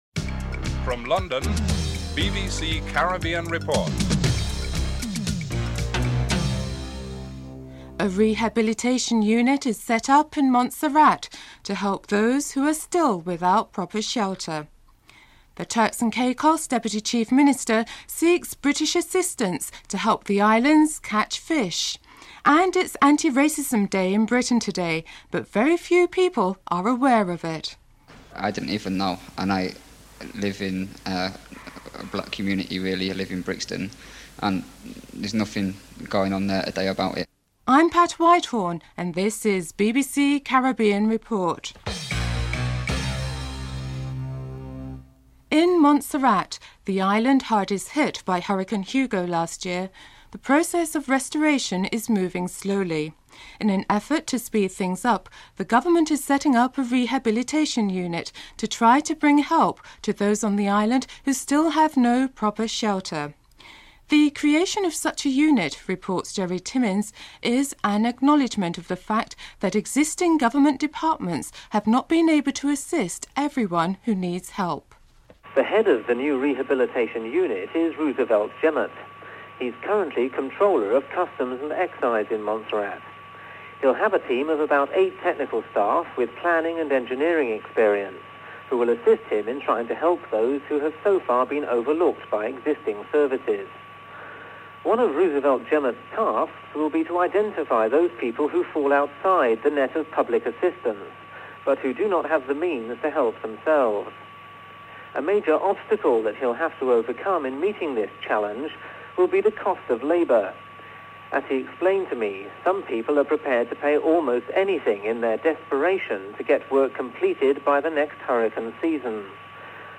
Report ends abruptly.
6. Sporting segment. Christopher Martin-Jenkins reports on the President's XI cricket match between England and the West Indies (13:08-14:23)